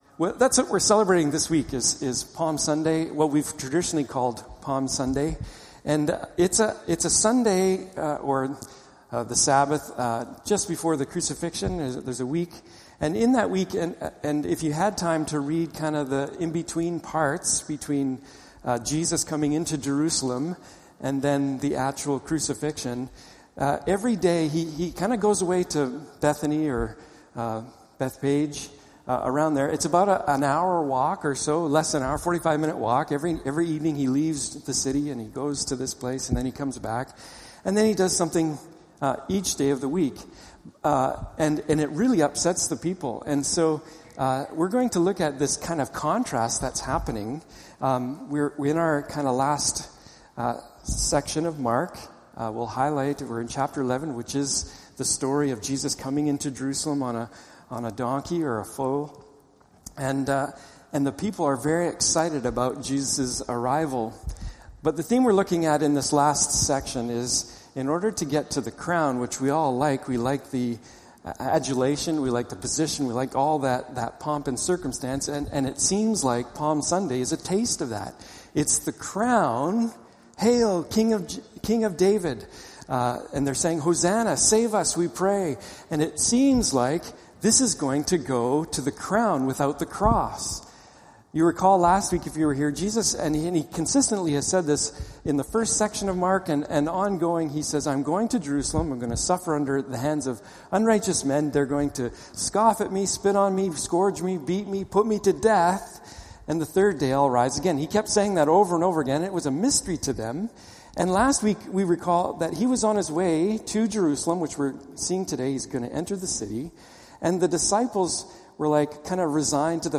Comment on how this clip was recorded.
Mark 11:12 Service Type: Morning Service « Jesus